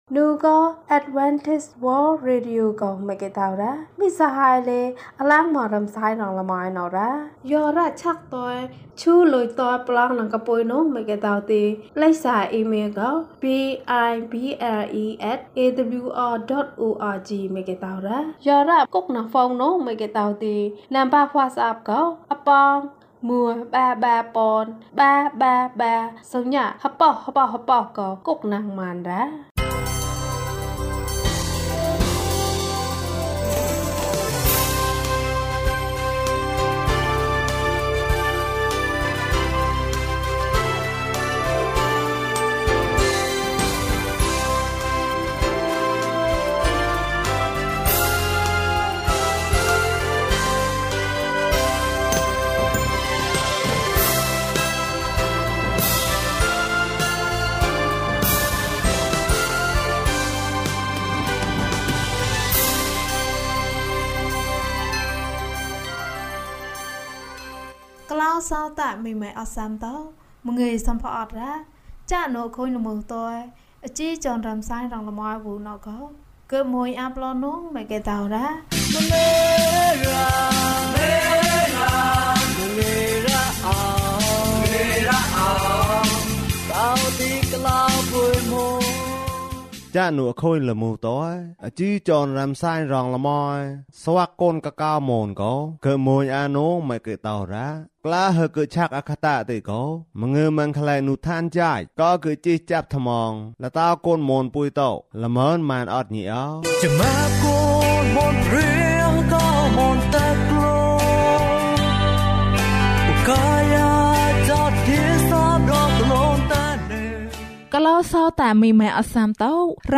နံနက်ခင်း၌ တောက်ပခြင်း။၀၂ ကျန်းမာခြင်းအကြောင်းအရာ။ ဓမ္မသီချင်း။ တရားဒေသနာ။